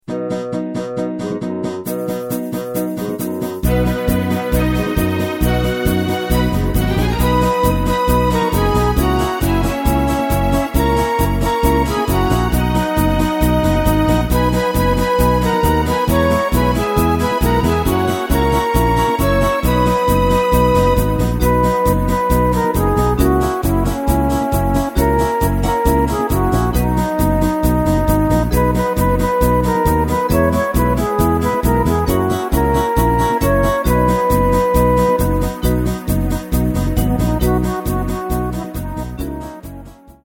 Takt:          2/4
Tempo:         135.00
Tonart:            B
Schlager aus dem Jahr 1971!